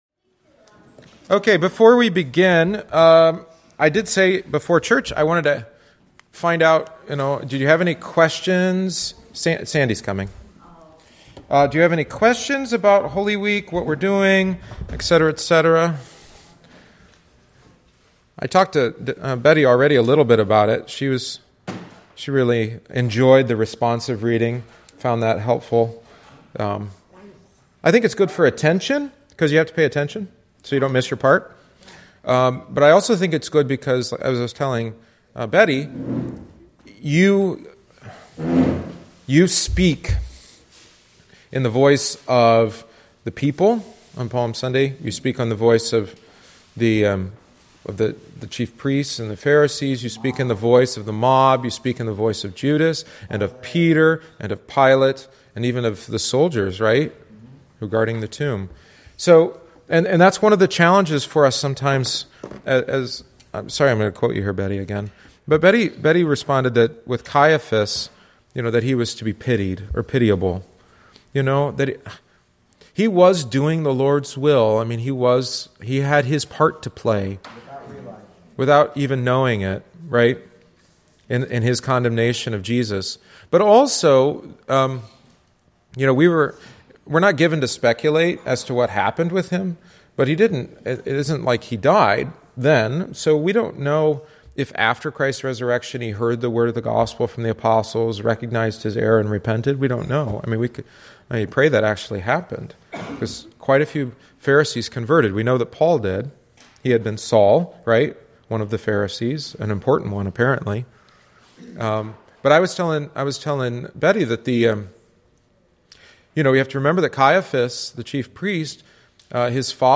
Catechesis on Palm Sunday